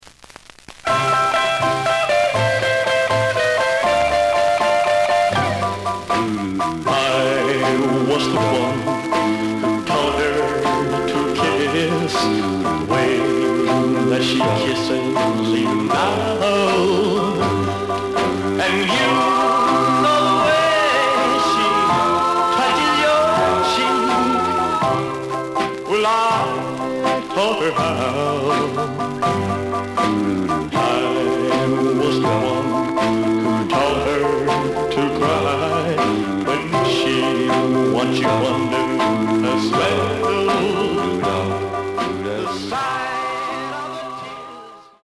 The listen sample is recorded from the actual item.
●Genre: Rhythm And Blues / Rock 'n' Roll
Noticeable noise on both sides.)